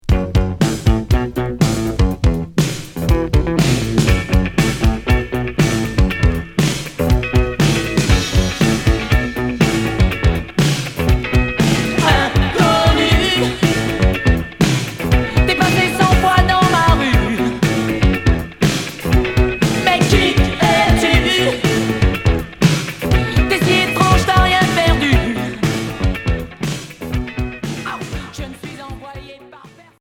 New wave FM Premier 45t retour à l'accueil